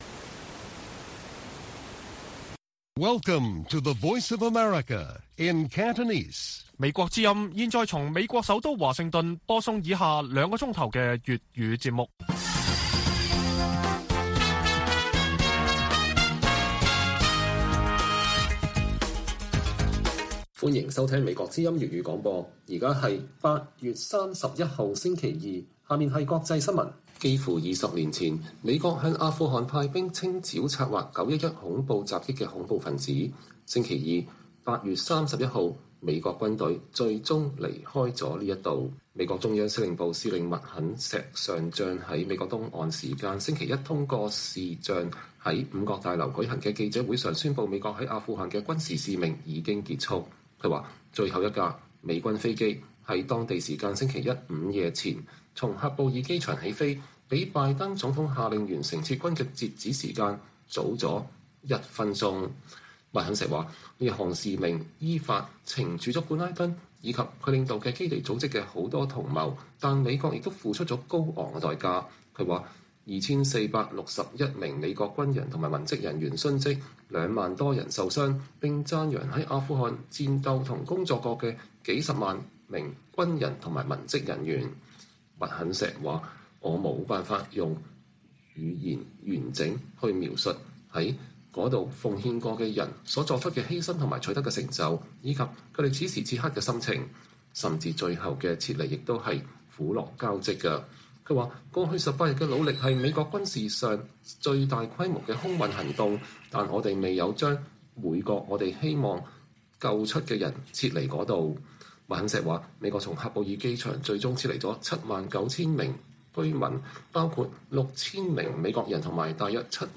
粵語新聞 晚上9-10點: 美軍宣佈終結阿富汗軍事任務